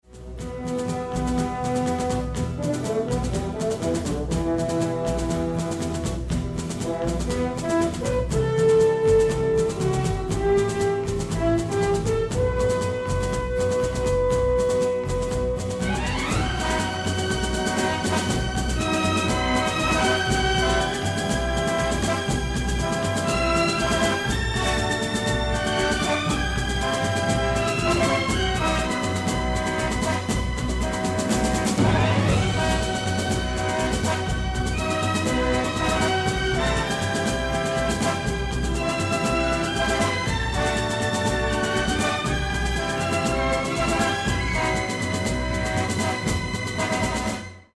Spaghetti Western epic medium instr.